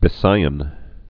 (bĭ-sīən, -sä-) also Bi·sa·ya (-sīə, -sä-)